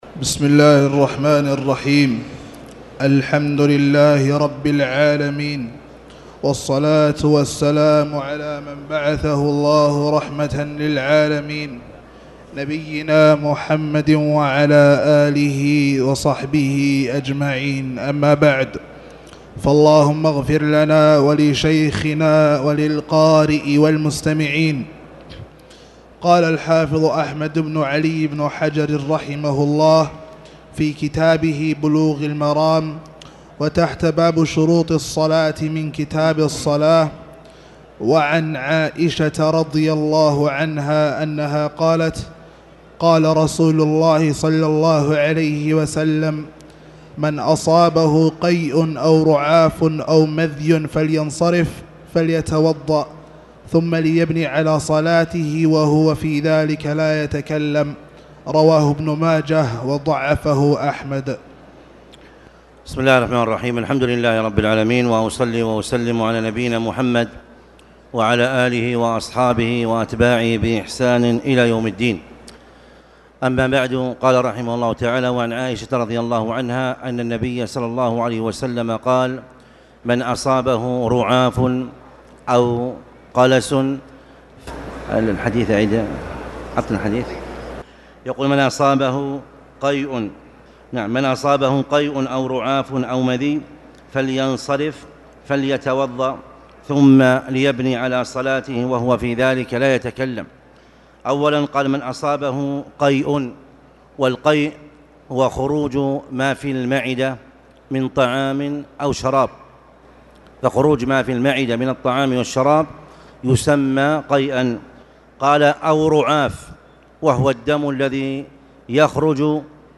تاريخ النشر ١ رجب ١٤٣٨ هـ المكان: المسجد الحرام الشيخ